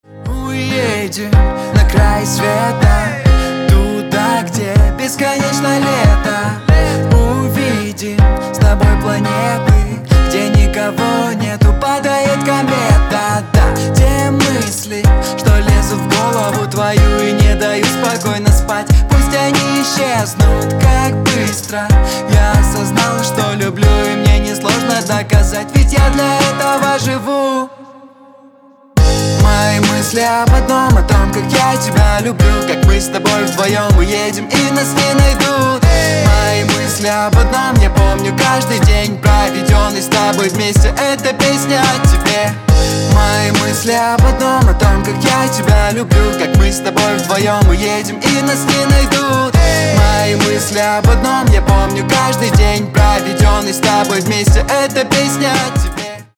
• Качество: 320, Stereo
поп
гитара
мужской вокал
акустика